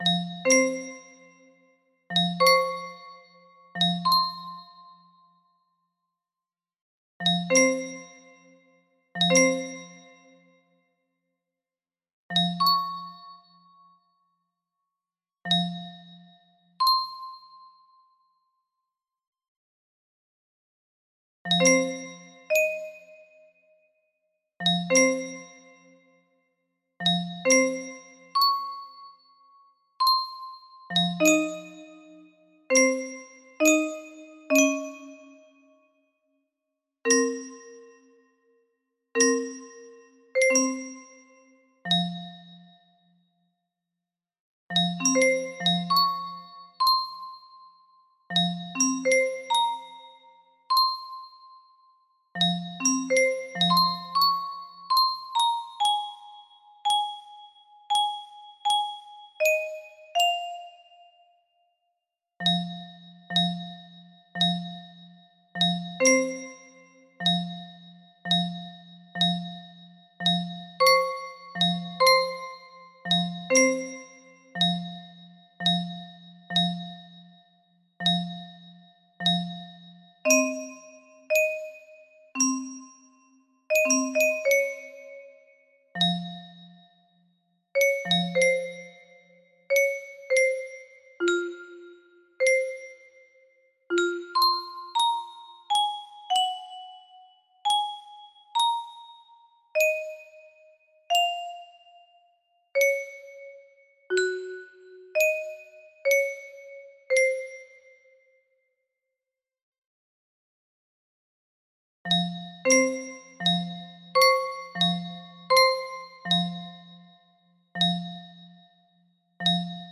Full range 60
something i just composed tonight on my midi keyboard.